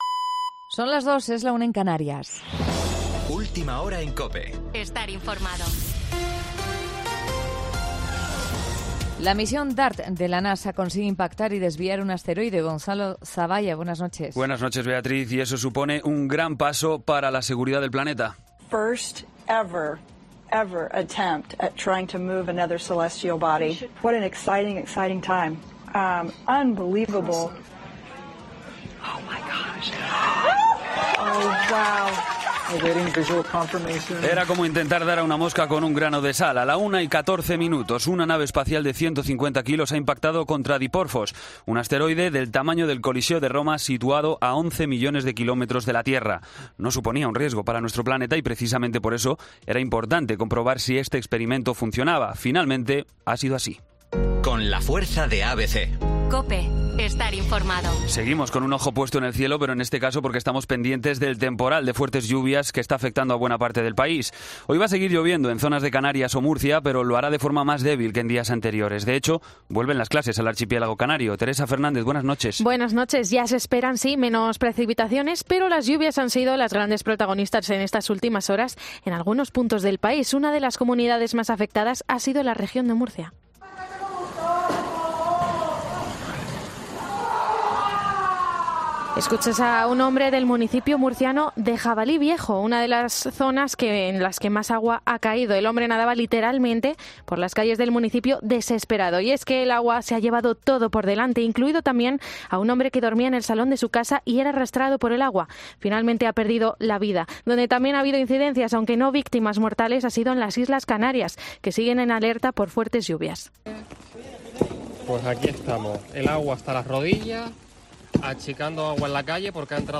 Boletín de noticias COPE del 27 de septiembre a las 02:00 hora
AUDIO: Actualización de noticias Herrera en COPE